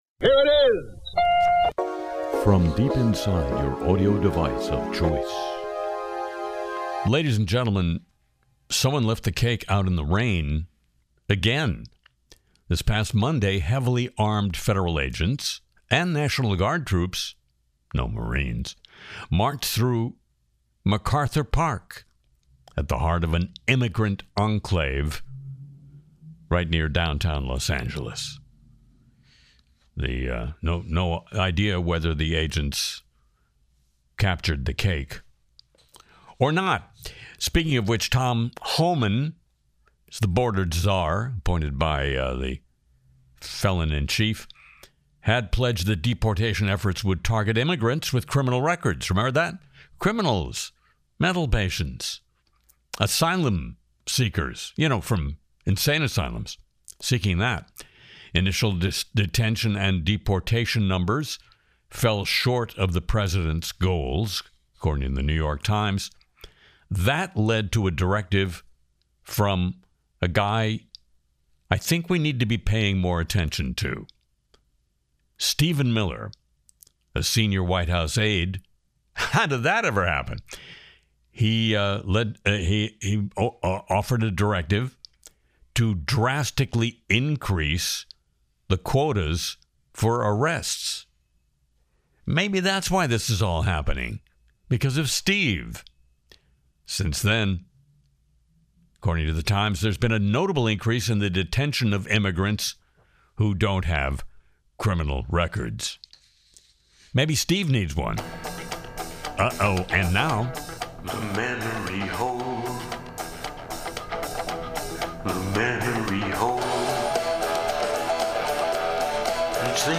Catch Harry Shearer’s Le Show: Hilarious MAGA Tonight skit, Ukraine’s nuclear history, Elon’s X purge, Grok 4, AI scandals, Trump tariffs, and ICE raid fears.